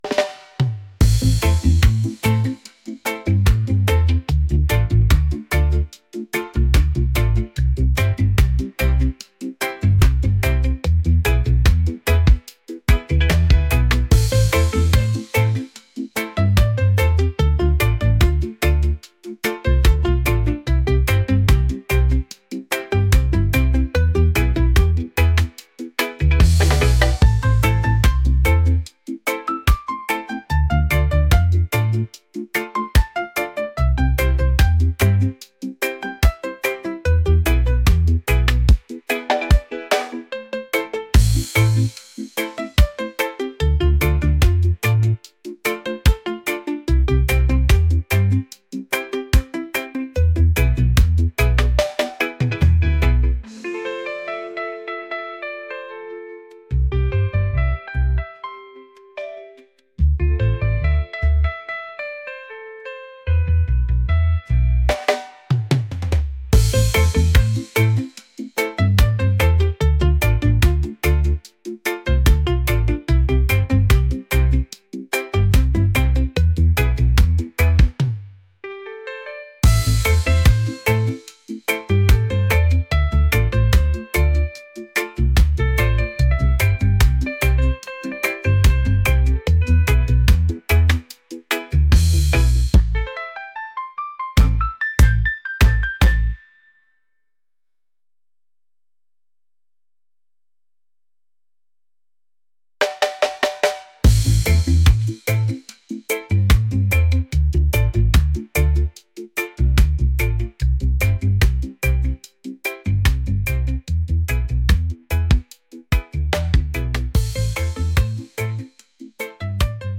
reggae | smooth | upbeat